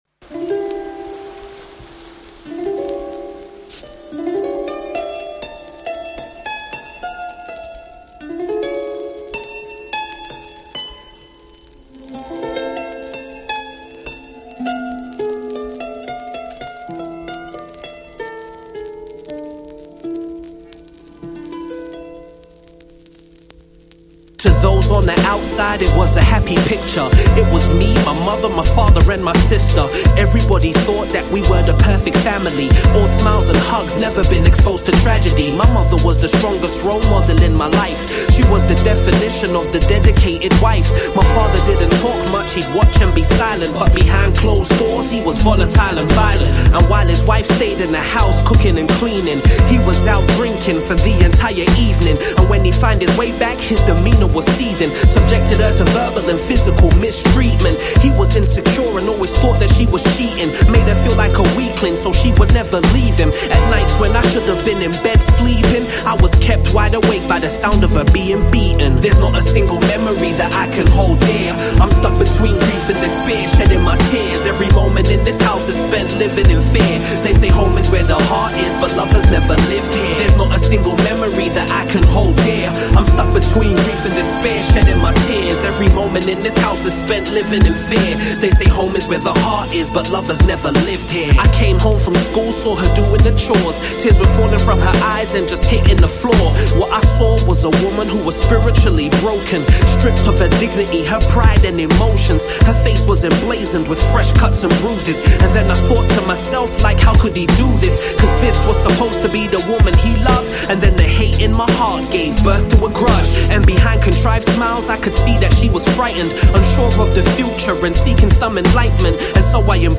UK mc